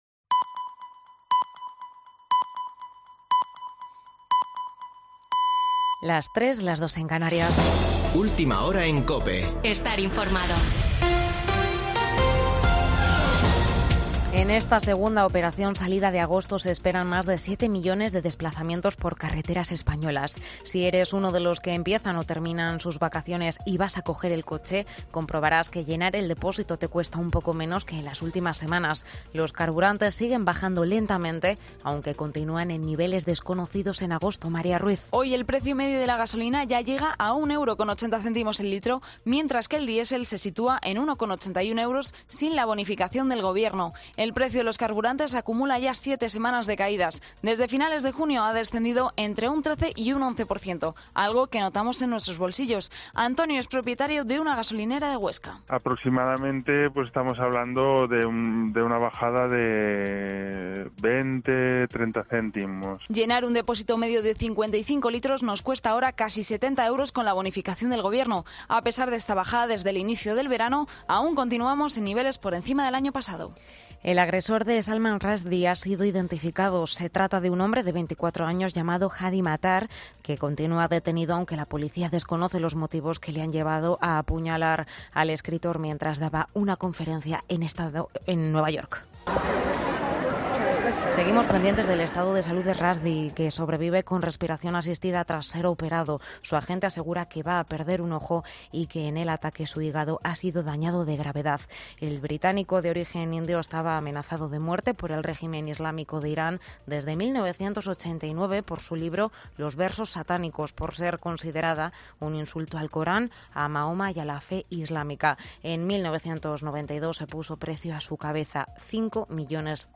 Boletín de noticias de COPE del 13 de agosto de 2022 a las 03.00 horas